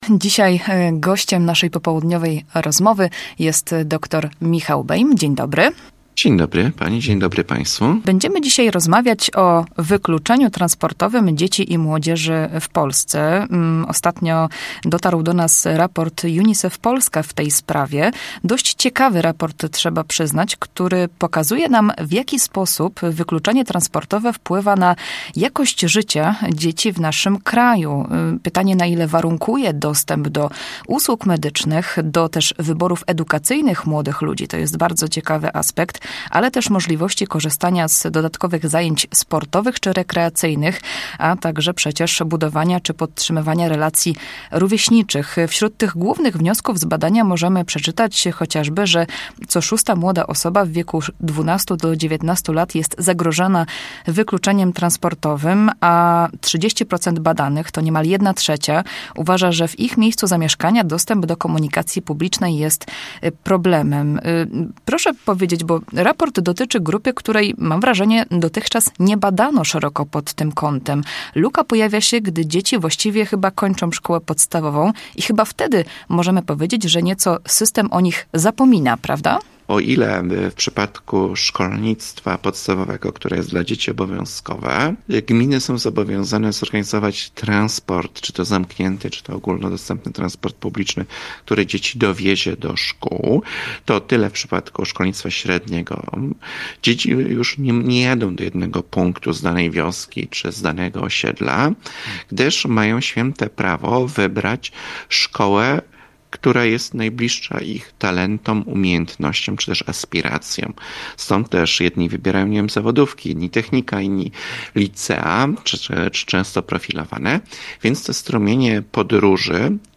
Popołudniowa rozmowa Radia Poznań